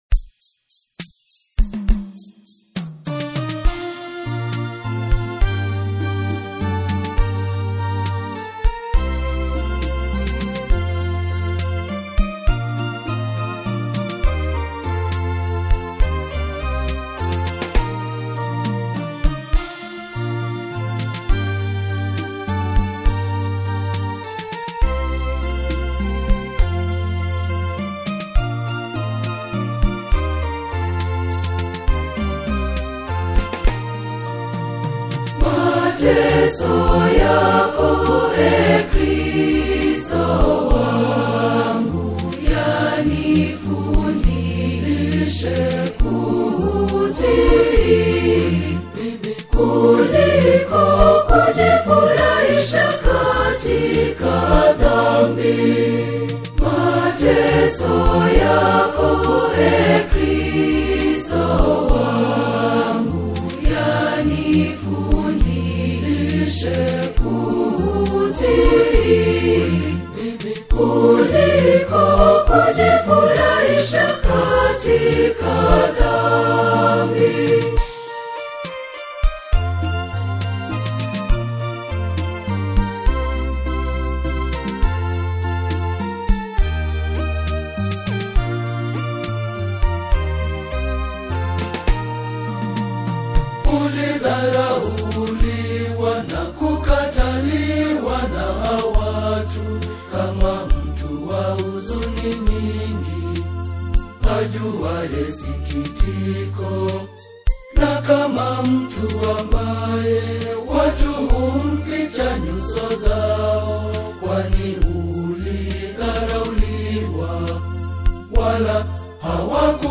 Swahili choral music